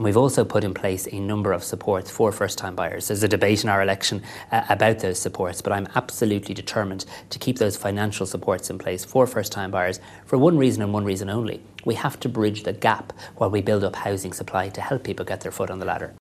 Simon Harris says first time buyers are also a priority: